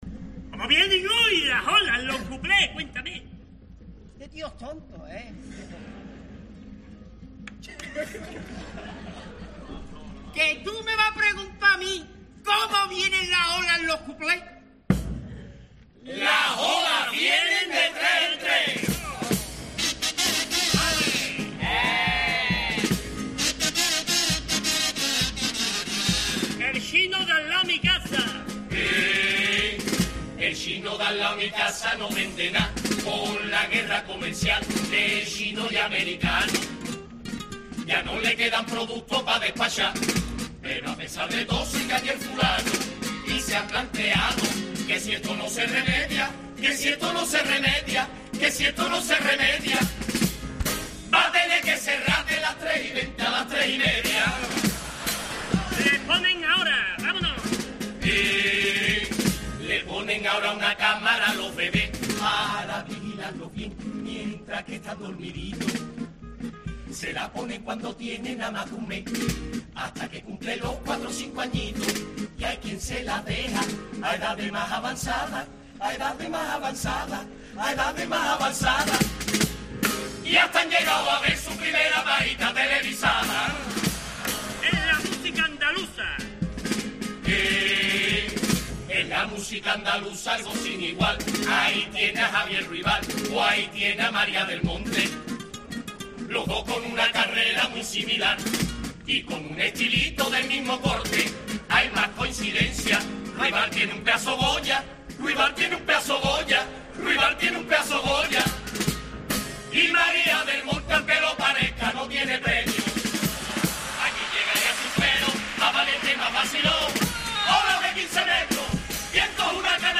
Cuplés de Los cadizfornia en la Gran Final